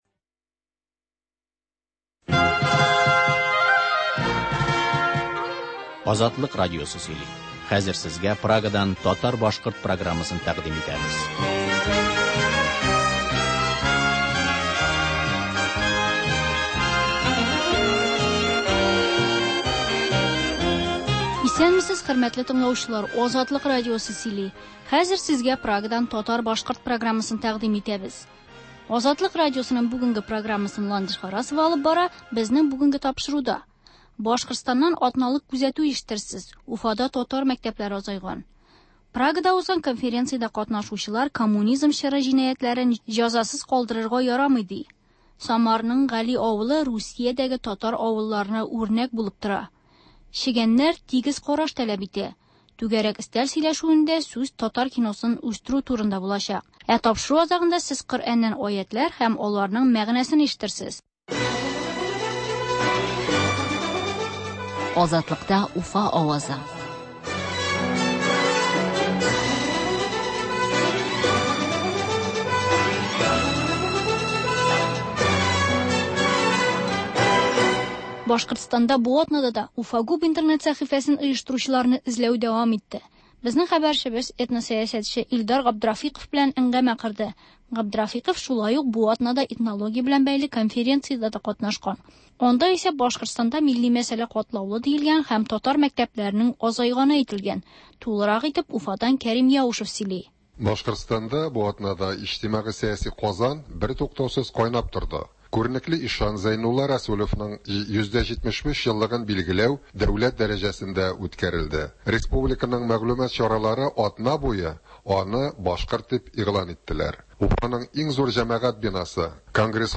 Азатлык радиосы бар атнага күз сала - Башкортстаннан атналык күзәтү - түгәрәк өстәл артында сөйләшү